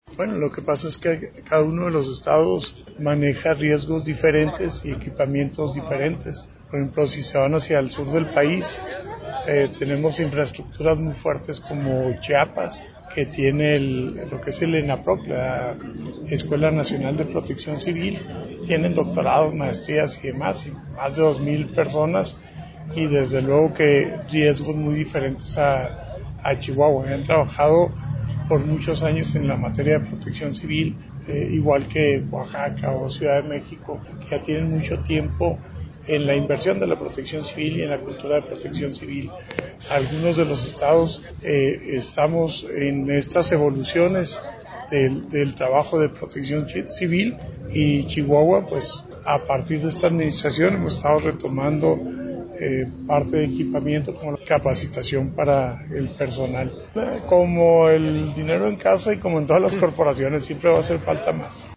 AUDIO: LUIS CORRAL TORRESDEY, COORDINACIÓN ESTATAL DE PROTECCIÓN CIVIL (CEPC)
Chihuahua, Chih.- El coordinador estatal de Protección Civil, Luis Corral Torresdey, explicó que los municipios como Chihuahua, Ciudad Juárez, Cuauhtémoc, son los que tienen actualizados sus atlas de riesgo, por lo que señaló que sí existe un rezago en esos mapas en otros municipios de la entidad y a nivel estatal.